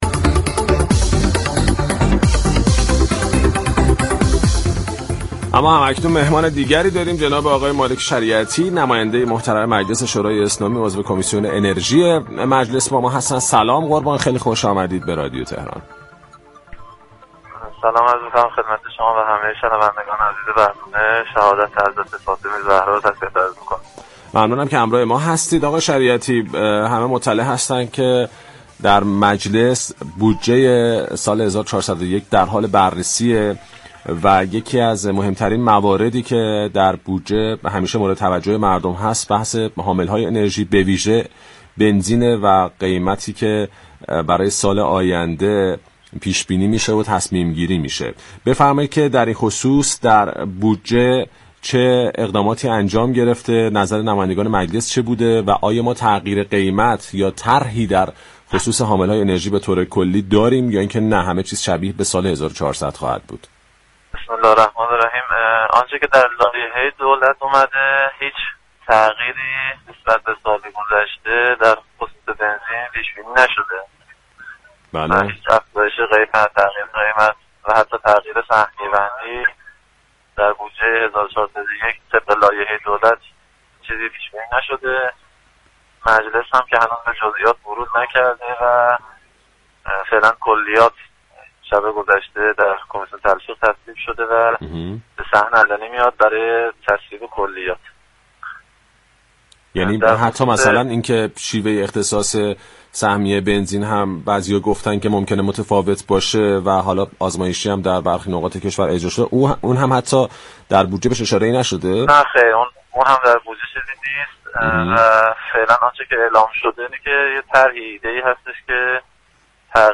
به گزارش پایگاه اطلاع رسانی رادیو تهران، مالك شریعتی نیاسر عضو كمیسیون انرژی مجلس شورای اسلامی در گفتگو با پارك شهر رادیو تهران درخصوص تغییر قیمت حامل‌های انرژی در لایحه بودجه سال 1401 گفت: طبق لایحه بودجه سال 1401 قیمت و نحوه تخصیص بنزین هیچ تغییری نخواهد داشت.